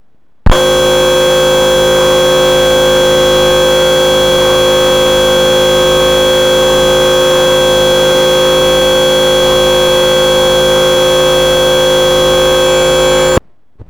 I recorded this with the parabolic microphone.
tonal modulation of thinking
Above is the tonal modulation of the thinking process. Try and focus in and find the tone that modulates.